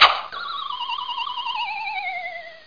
00738_Sound_boomerang.mp3